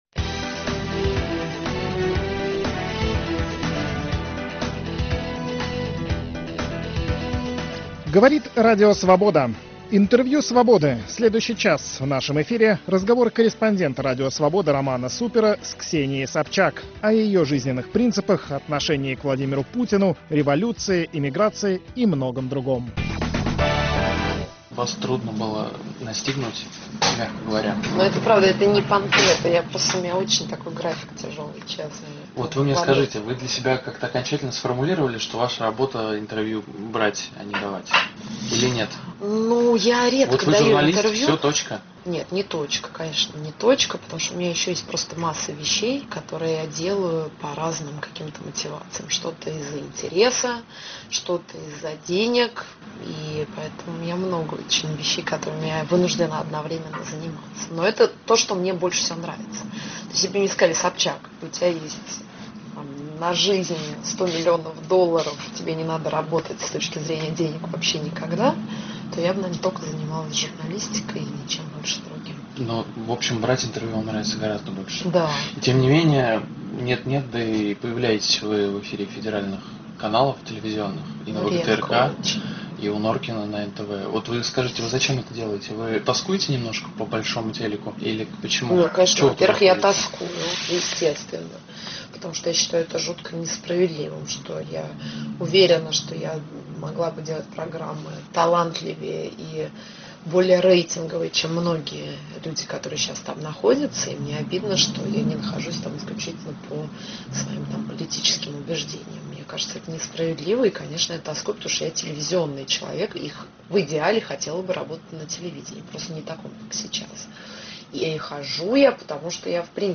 Время Свободы - Интервью
Телеведущая Ксения Собчак в интервью корреспонденту Радио Свобода рассказывает о своем отце - мэре Петербурга, его дружбе с Владимиром Путиным, а также делится мнением о будущем оппозиционного движения в России